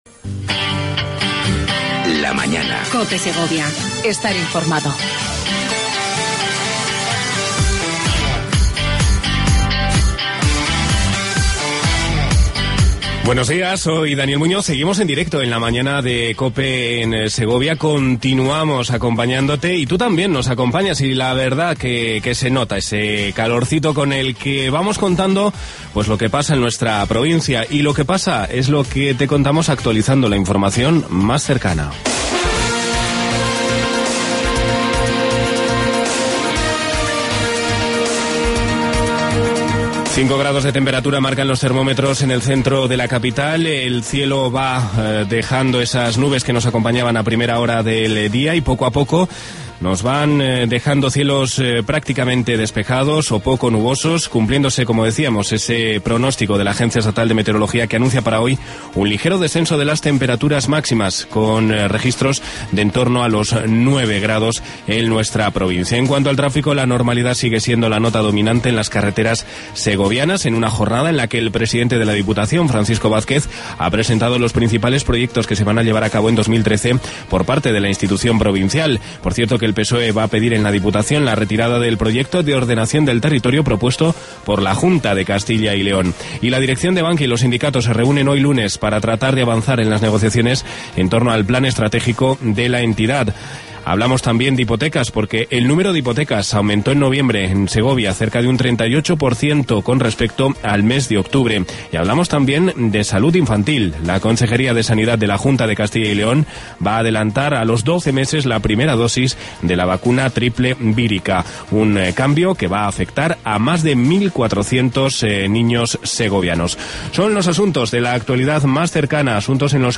Redacción digital Madrid - Publicado el 28 ene 2013, 19:55 - Actualizado 14 mar 2023, 16:36 1 min lectura Descargar Facebook Twitter Whatsapp Telegram Enviar por email Copiar enlace Entrevista a Pilar Sanz, subdelegada del Gobierno en Segovia, a punto de cumplirse un año de su llegada al cargo. También repasamos la agenda del día.